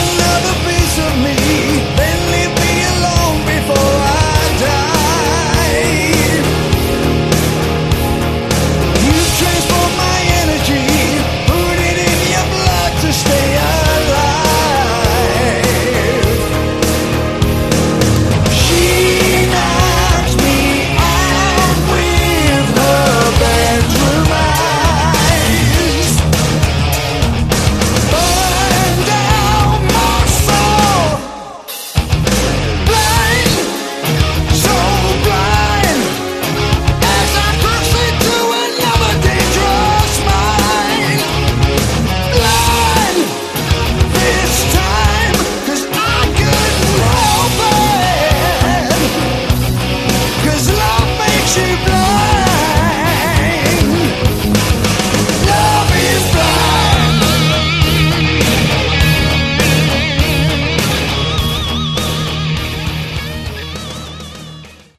Category: AOR
vocals